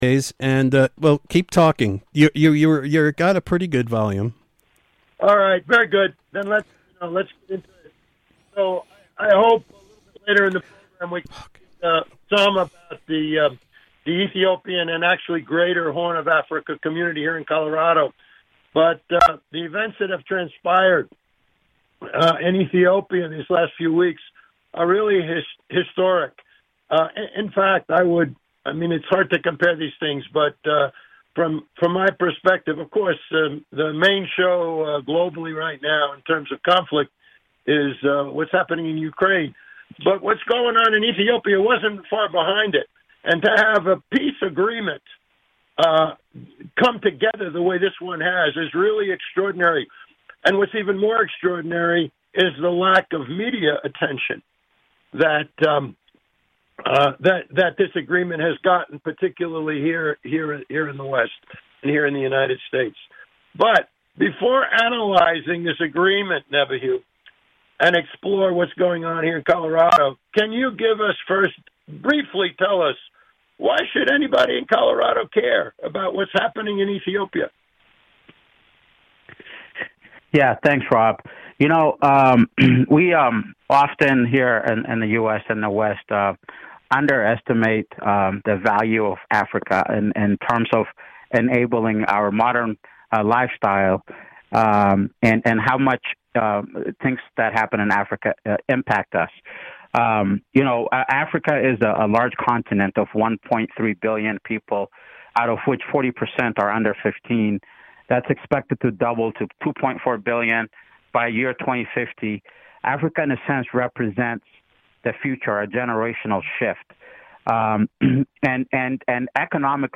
(Note: – the audio is edited so as to concentrate on the content of the interview with the introductions cut out.